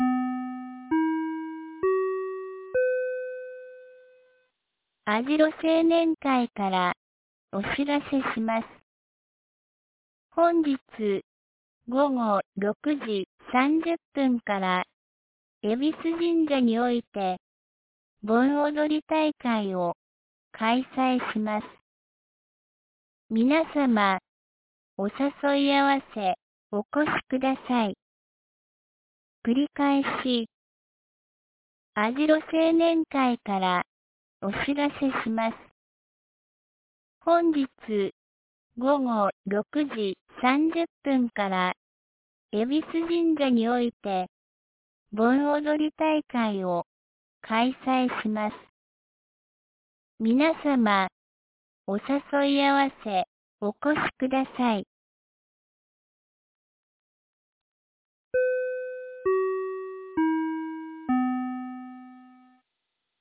2019年08月12日 17時12分に、由良町より網代地区へ放送がありました。